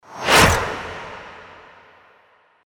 FX-619-WIPE
FX-619-WIPE.mp3